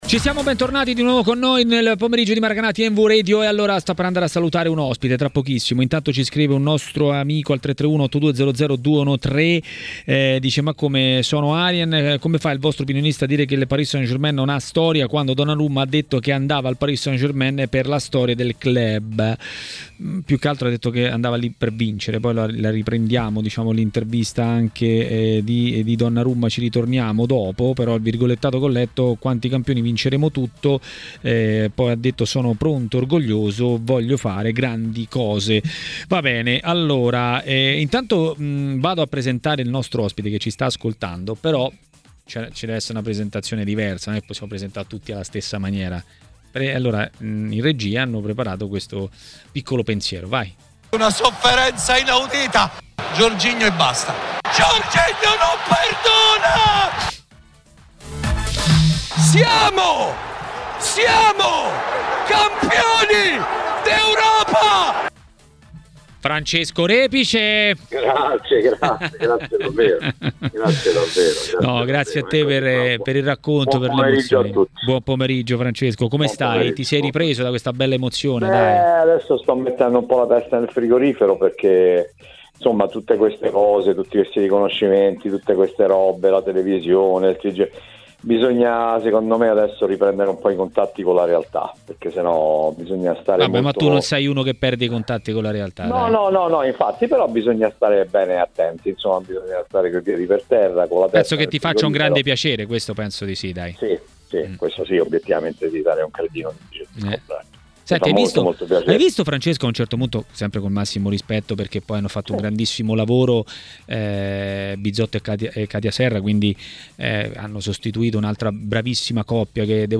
© registrazione di TMW Radio